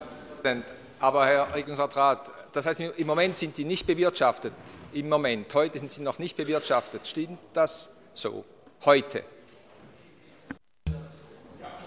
18.9.2013Wortmeldung
Session des Kantonsrates vom 16. bis 18. September 2013